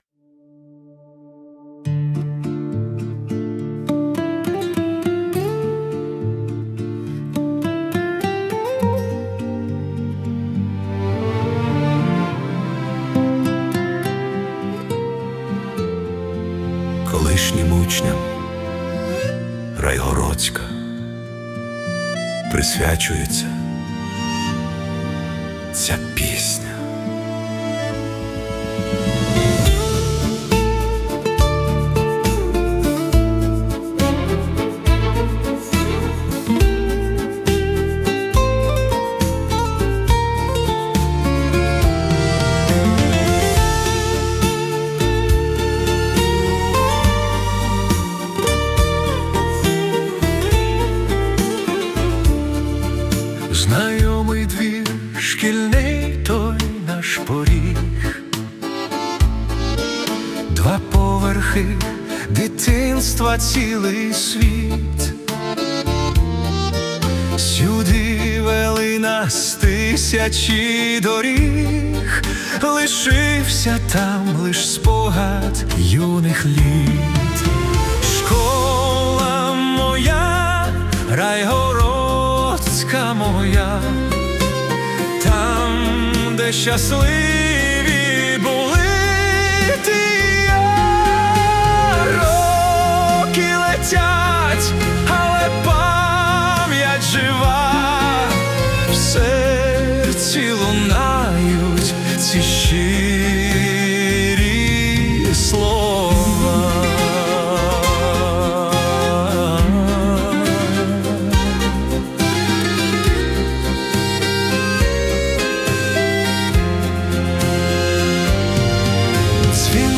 Cinematic Waltz / Nostalgic